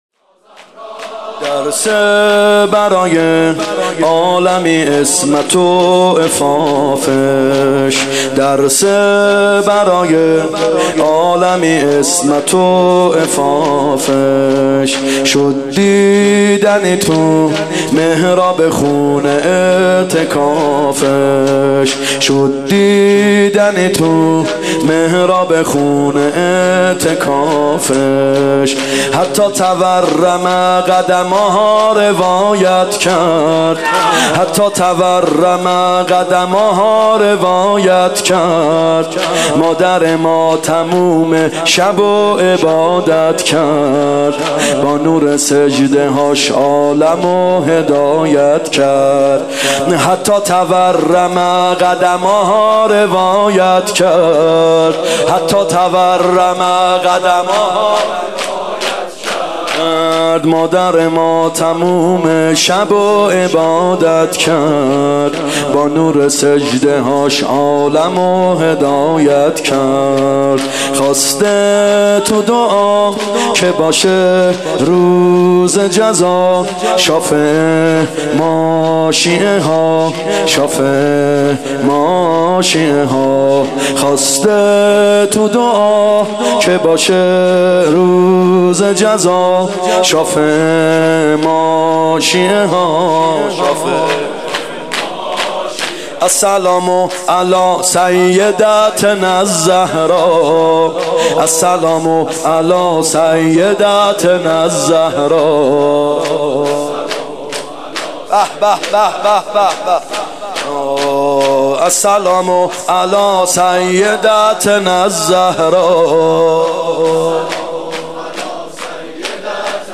فاطمیه اول 95 شور
فاطمیه اول هیئت یامهدی (عج)